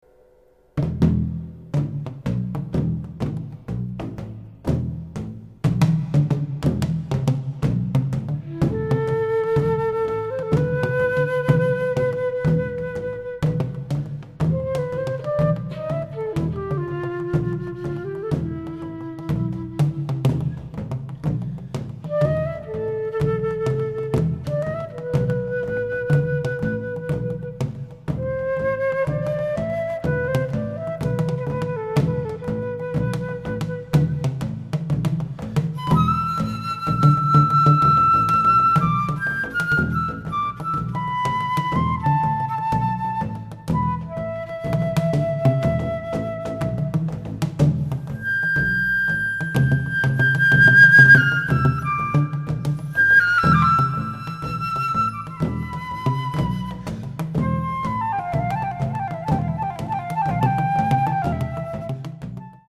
at studio Voice
バス・フルート
フルート
ハチノスギ太鼓(創作楽器)
竹琴(自作楽器)
タム